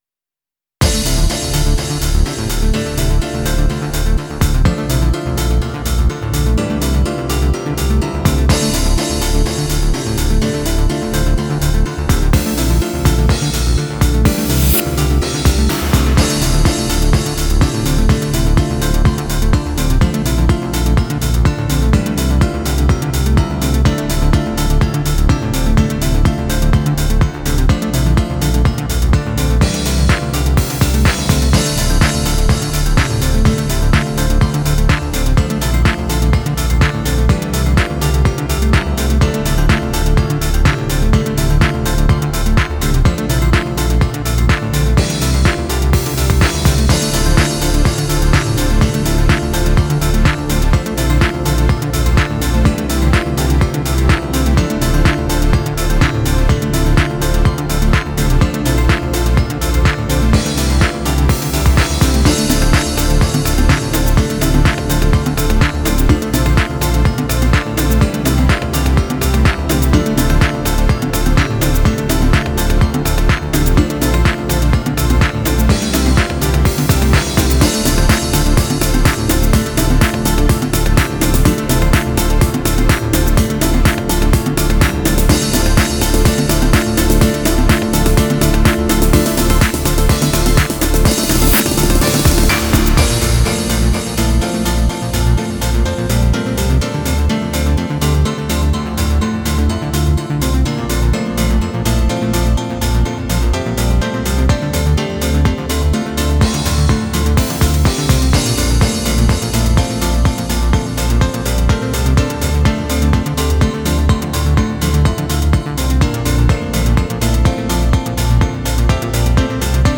HOUSE（BPM１２５）
イメージ：海王星　ジャンル：Dream House、Piano House